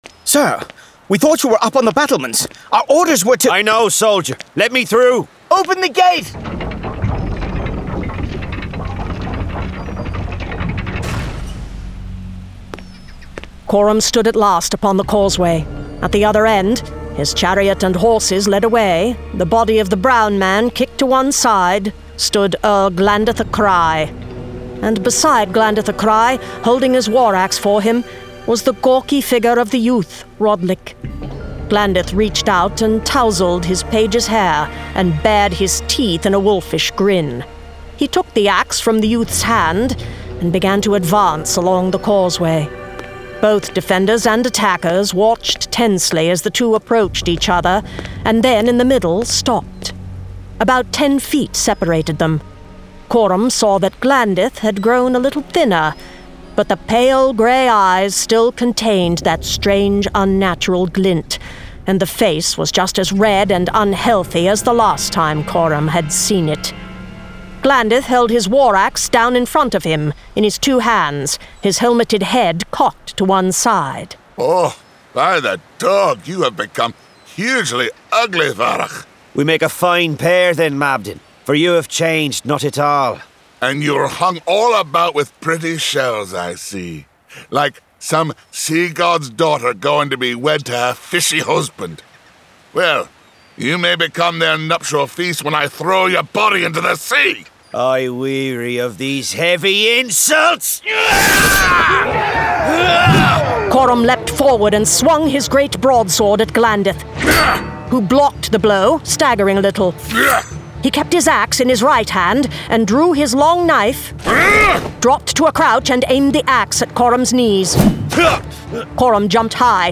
Full Cast. Cinematic Music. Sound Effects.
Genre: Fantasy